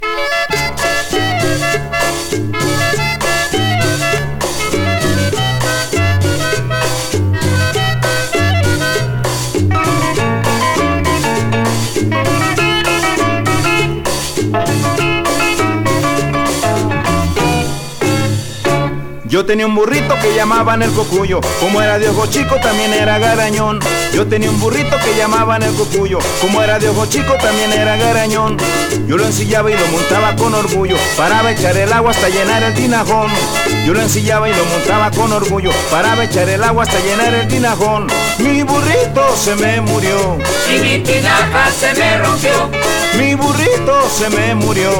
Latin, Cumbia, Boogaloo　USA　12inchレコード　33rpm　Mono